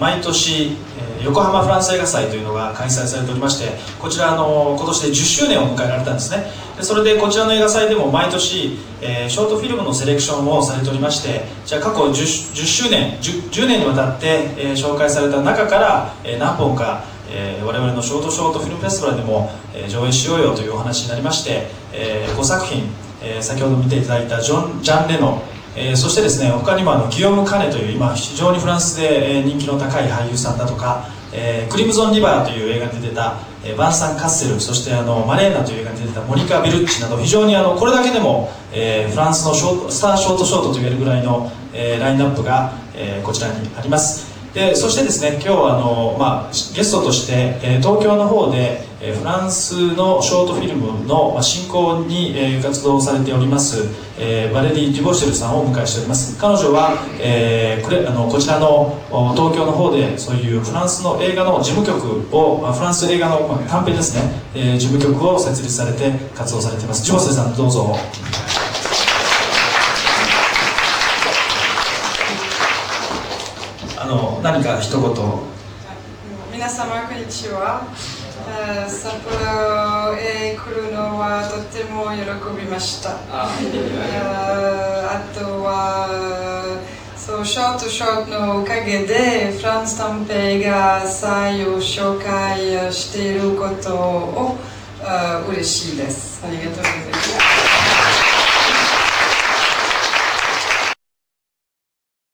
フレンチ・ショート・ショートの紹介(音声)